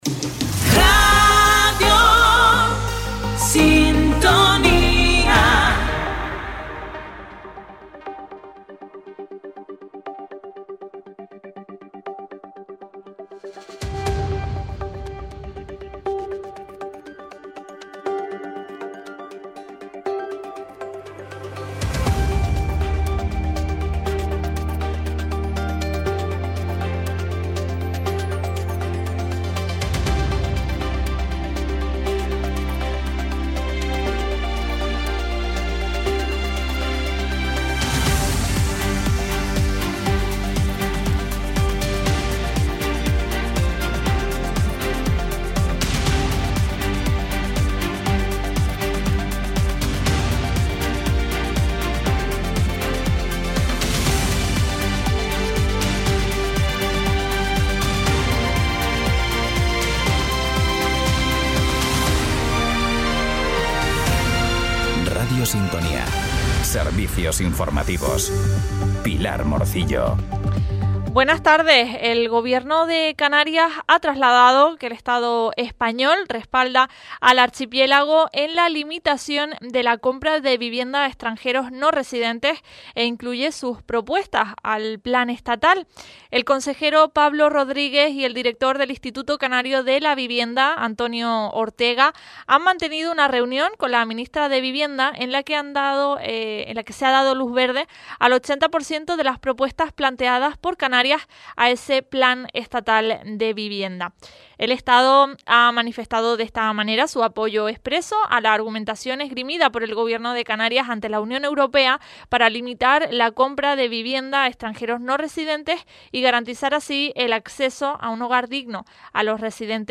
Cada día, desde Radio Sintonía, puedes seguir toda la información local y regional en nuestro espacio informativo. En él te contamos, en directo, las noticias más importantes de la jornada, a partir de las 13:15h.